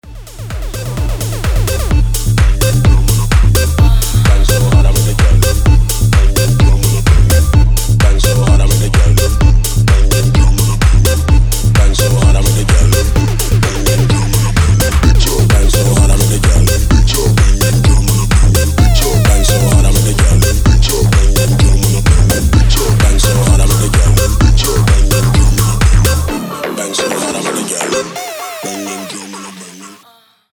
tech house
edm